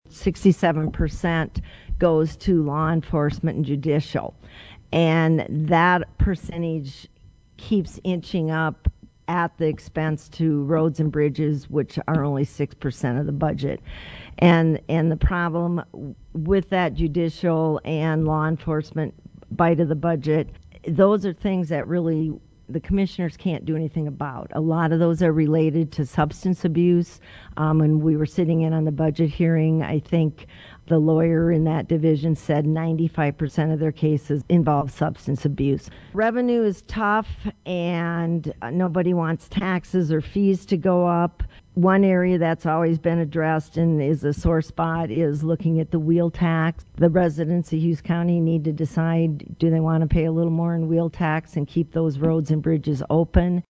During a candidate forum hosted by the League of Women Voters Pierre and Dakota Radio Group, the candidates agreed lack of revenue, roads and bridges and upgrades needing to be done in the courthouse in Pierre are three major issues for Hughes County right now.